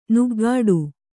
♪ nuggāḍu